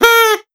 Duck call 03.wav